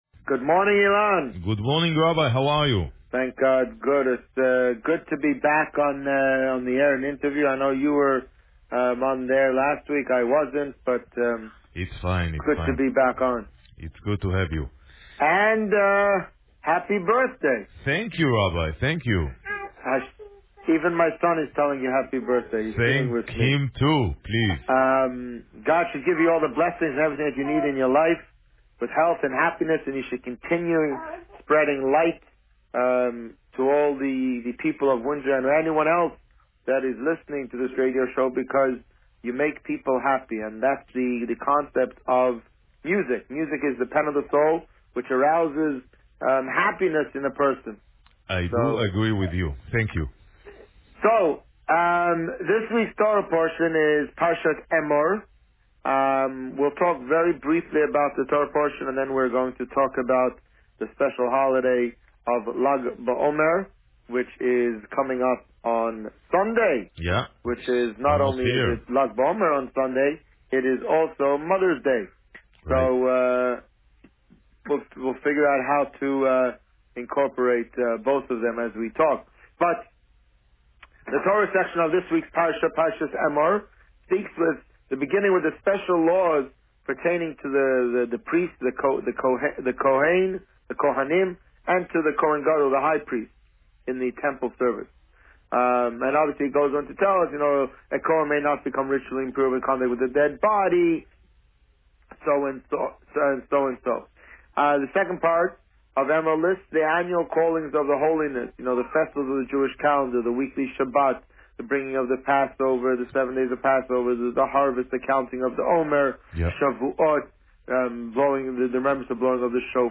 On May 11, 2017, the Rabbi spoke about Parsha Emor, as well as the upcoming Lag B'Omer celebration that coincides with Mother's Day. Listen to the interview here.